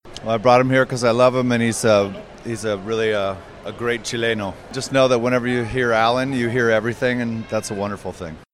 Josh Homme llegó hoy martes 20 a las 7 de la mañana, y apenas salió del aeropuerto se tomó un par de segundos para conversar en exclusiva con Futuro.